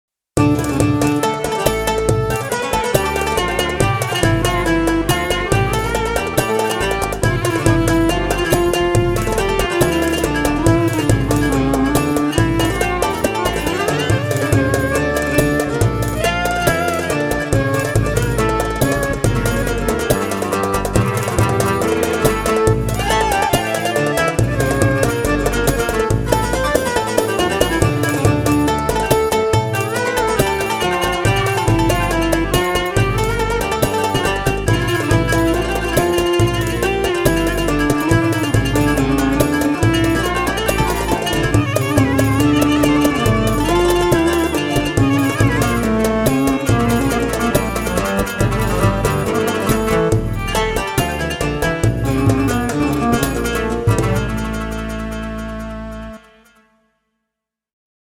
Or 700 v2 plus en güzel ritimleri